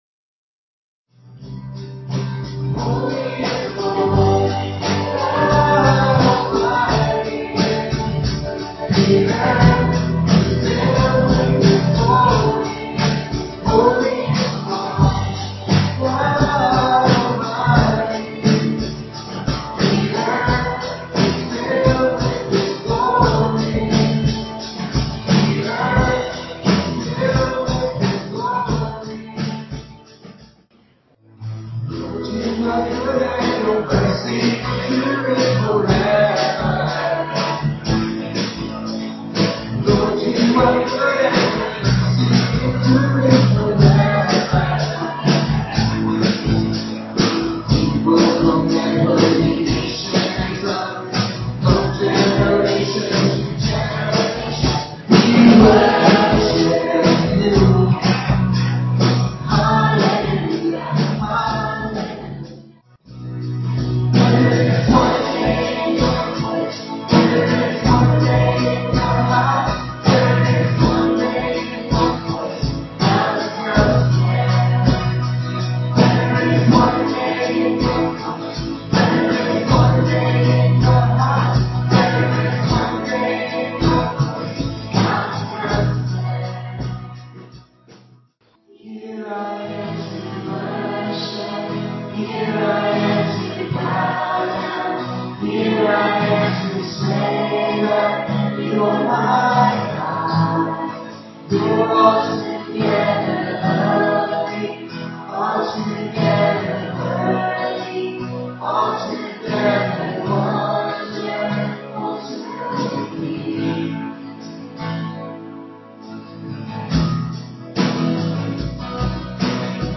PLAY David: Heroic Faith (Part 4), June 5, 2011 Scripture: 1 Samuel 23:9-16; 24:1-7. Message
at Ewa Beach Baptist Church. EBBC Worship Band